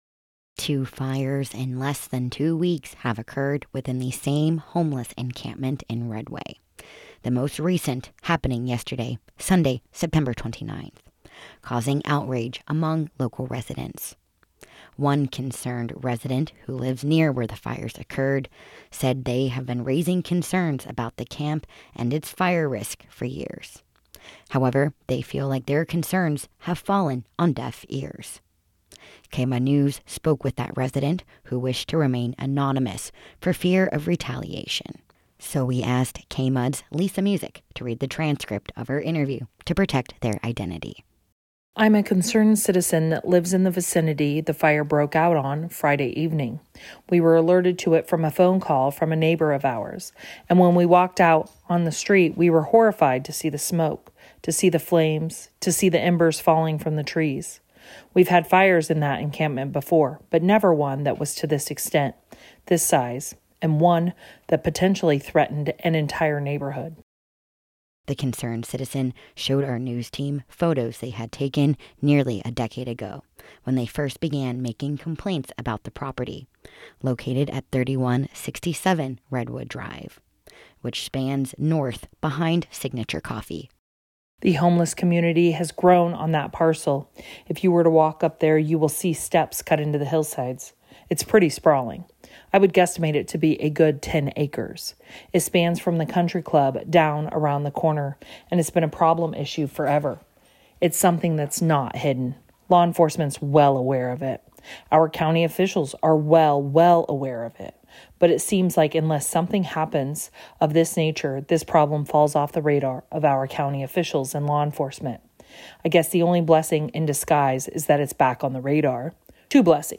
Two fires in less than two weeks have erupted at the same homeless encampment in Redway, leaving local residents outraged. The latest blaze on Sunday, September 29th, has one nearby resident speaking out, telling KMUD News they've raised fire safety concerns for years but feel their warnings have been ignored.